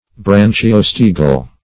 Branchiostegal \Bran`chi*os"te*gal\, a. [Gr.